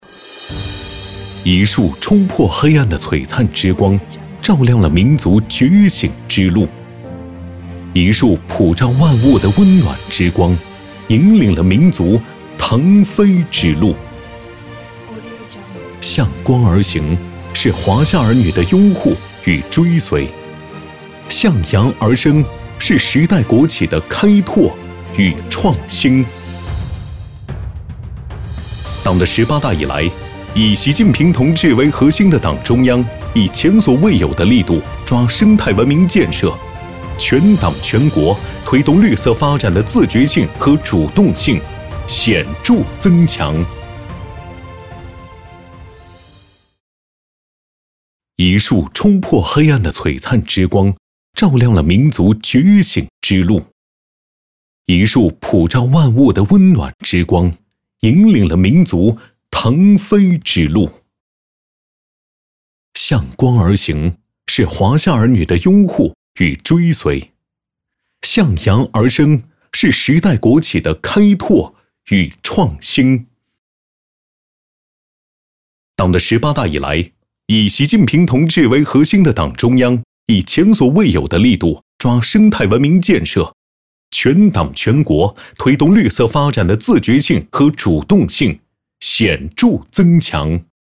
专注高端配音，拒绝ai合成声音，高端真人配音认准传音配音
男37